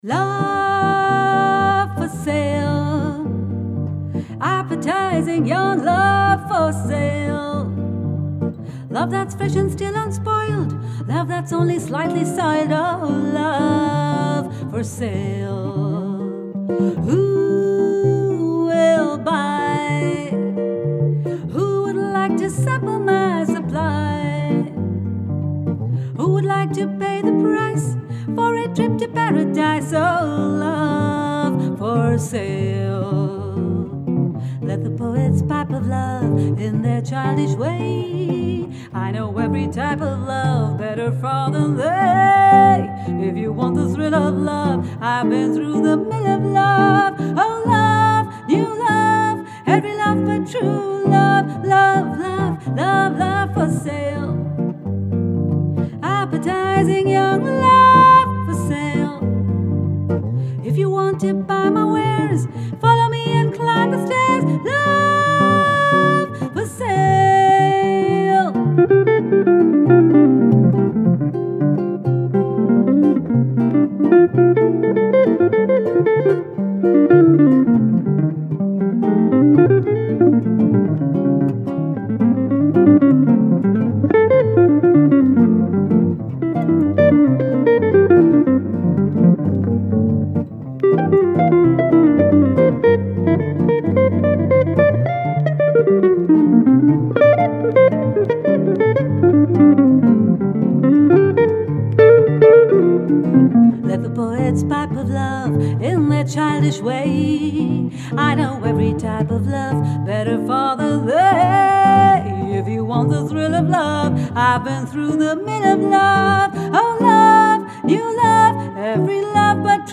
Gitarre & Gesang